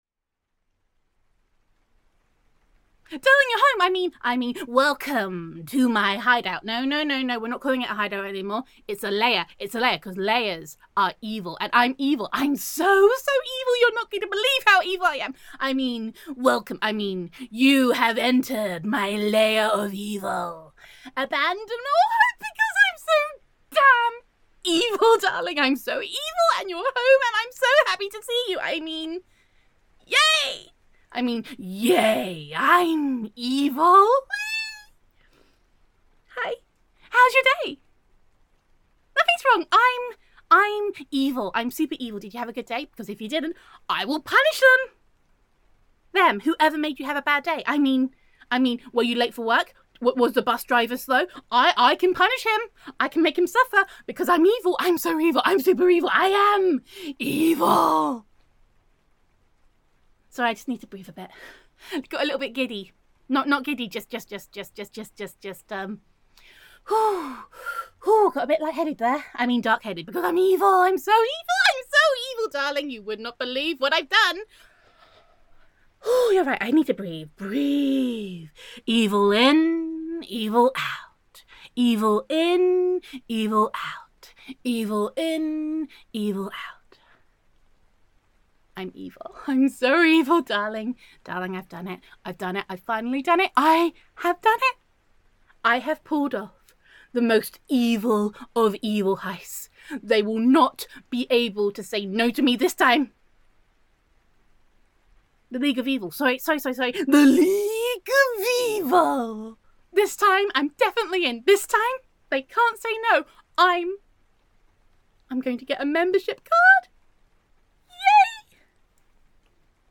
[F4A] I Am Evvvvviiiiilllllll
[Wife Roleplay][Excited Squeaking]